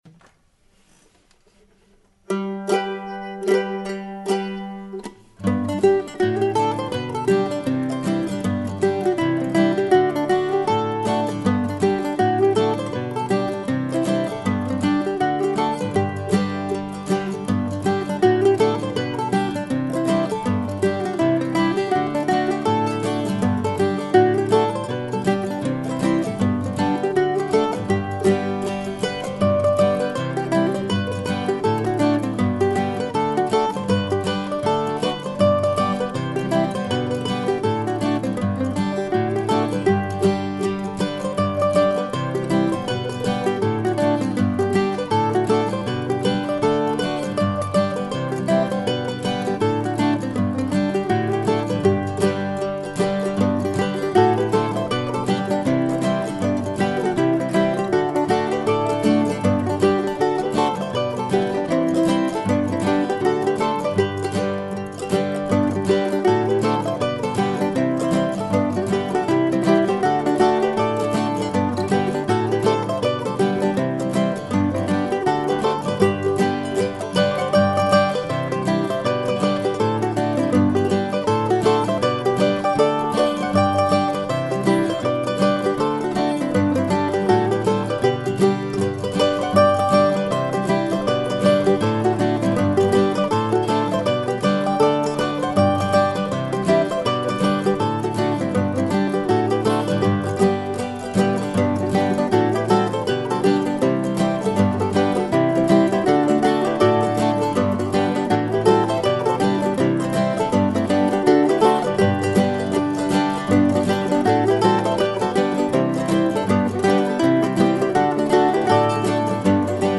I've used the Ekin Ave. title for a couple of different hornpipes in the last few years so if you have another tune from me with the same title scratch it out and call it something else.